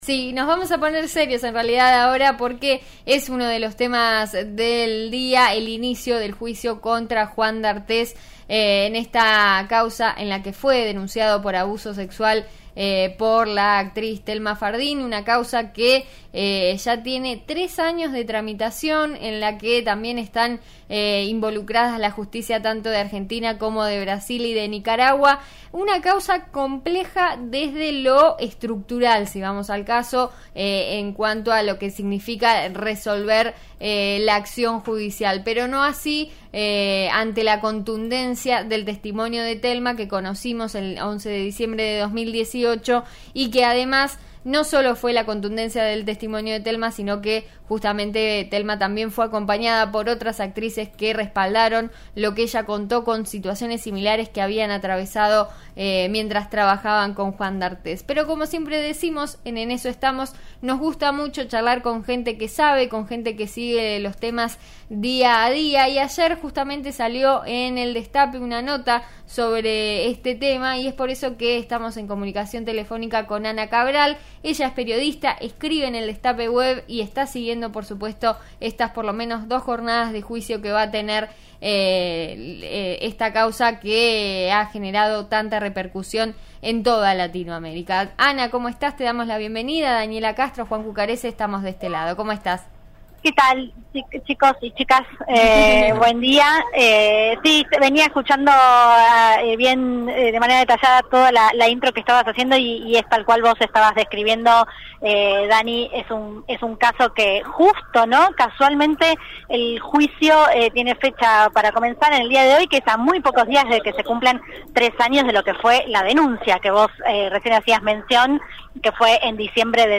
'En eso estamos' charló con la periodista